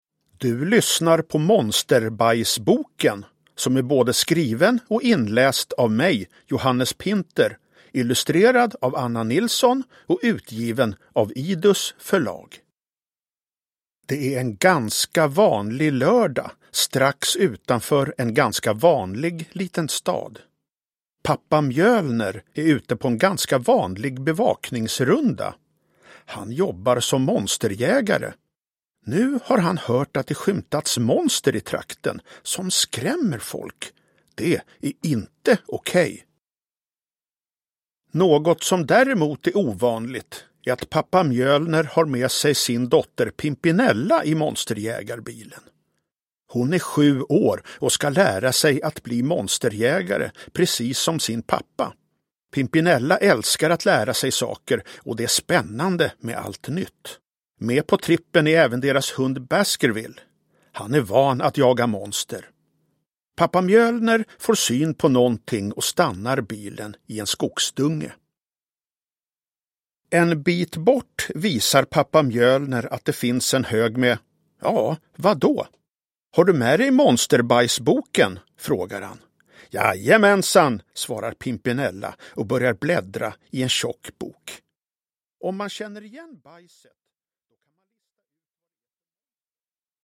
Monsterbajsboken (ljudbok) av Johannes Pinter